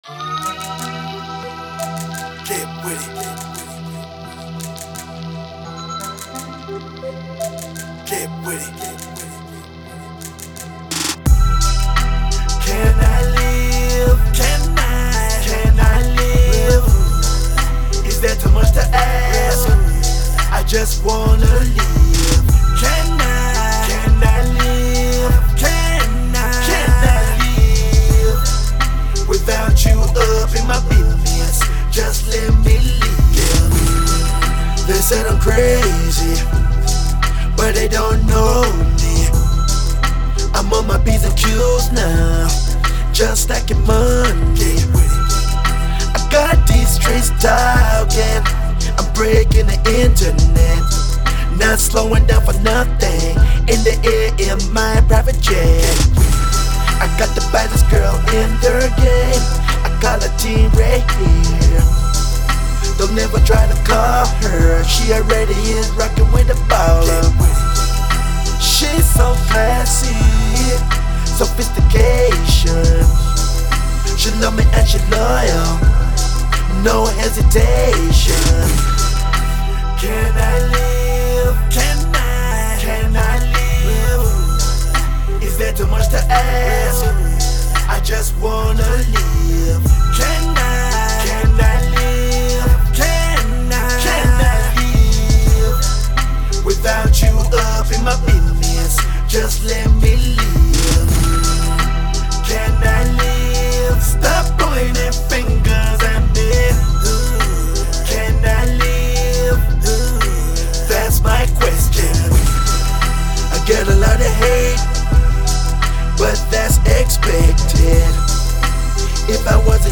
Snap Music, Street Blues